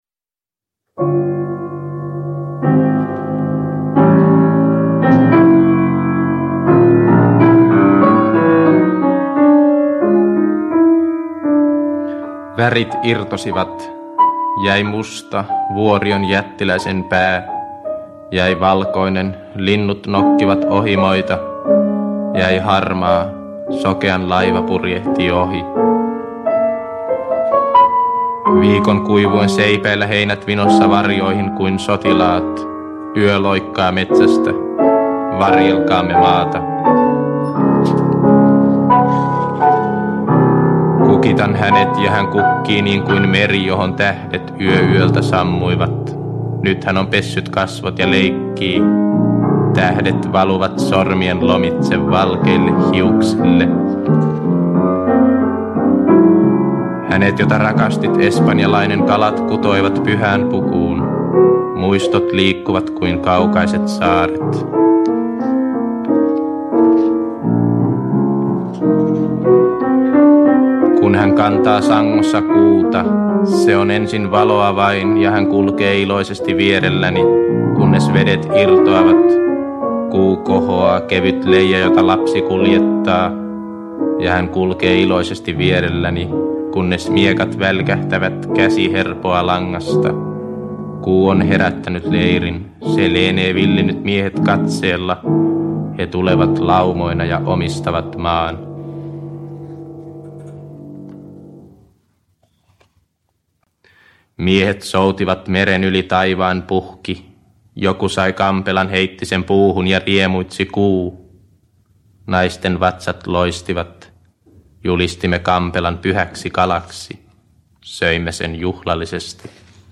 Valikoima Pentti Saarikosken runoja vuosilta 1958-1962. Rakastetut tekstit avautuvat kuulijalle kahdella äänellä: